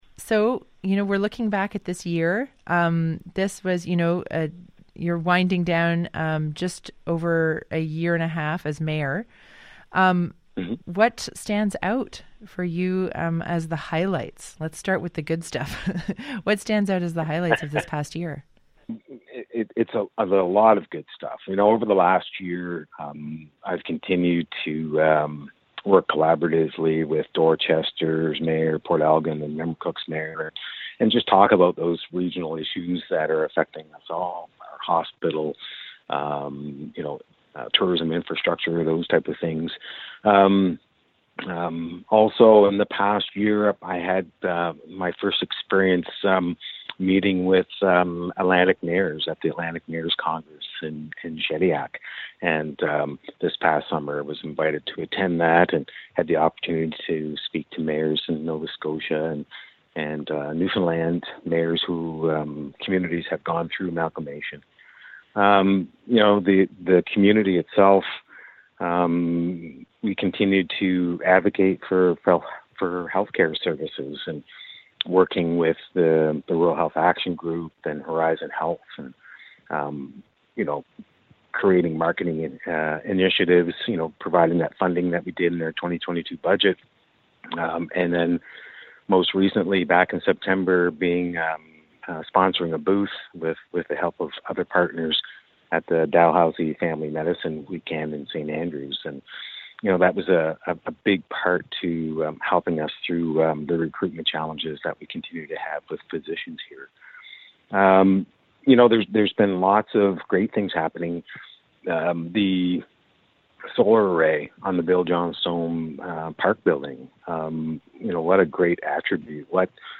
CHMA called up Mesheau in mid-December to reflect on his time in office, and the tumultuous year in local politics: